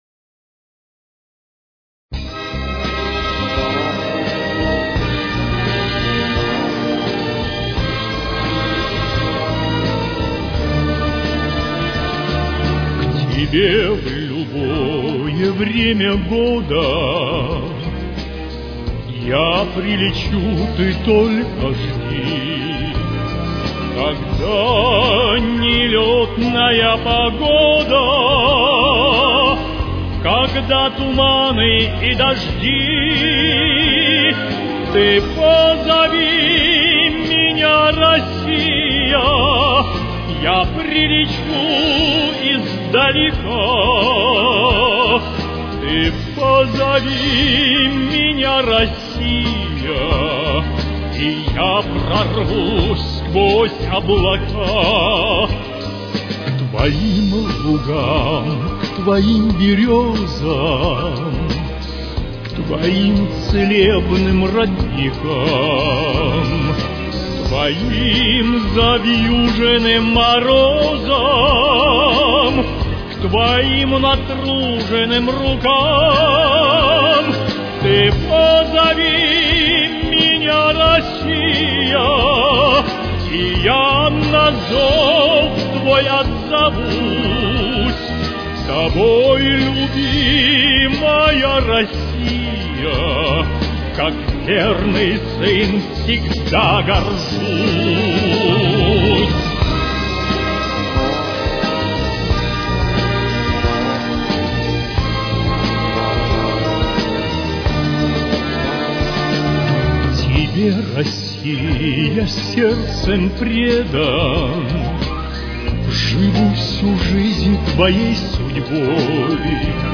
Соль минор. Темп: 87.